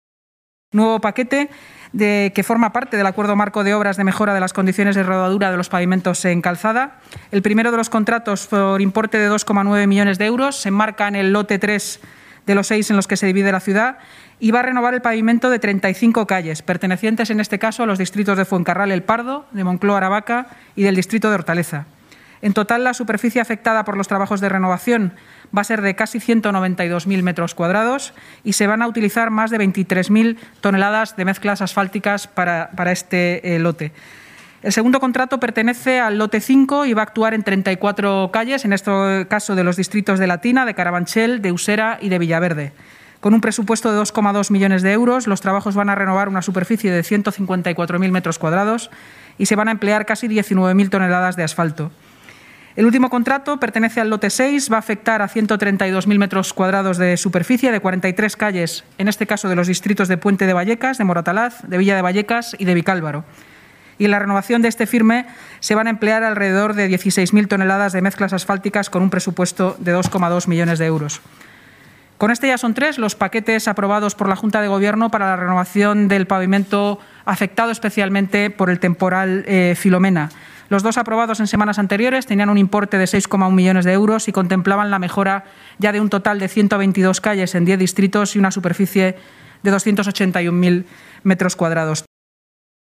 Nueva ventana:La portavoz municipal, Inmaculada Sanz, explica en la rueda de prensa el tercer paquete aprobado para la Operación Asfalto